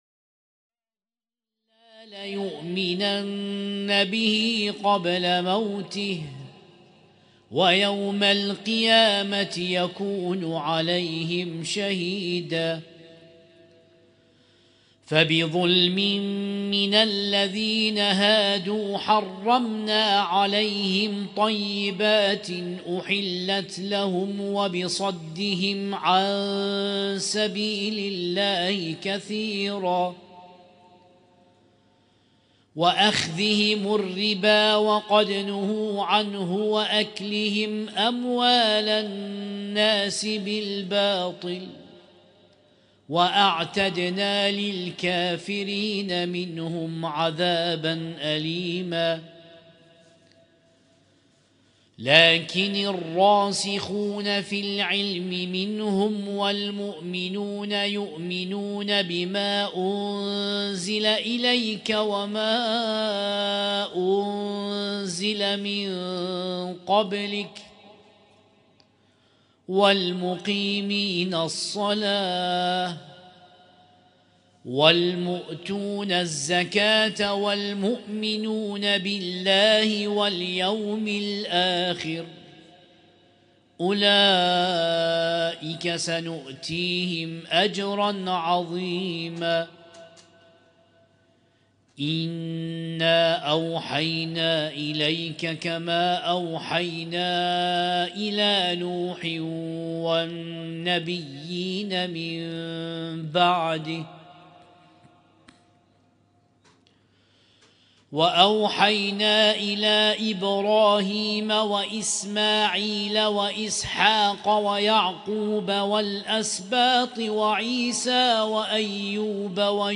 القارئ: القارئ